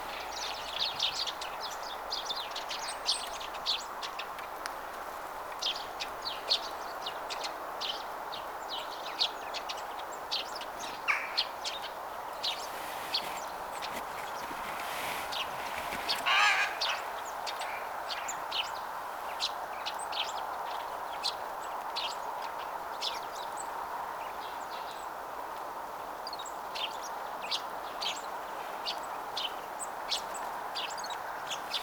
huomioääni, joka kuultiin joitakin päiviä sitten.
Ollen kuitenkin selvästi erilainen.
Arvaukseni on idänturturikyyhky,
arvaan_etta_tassa_huomioaantelee_idanturturikyyhky.mp3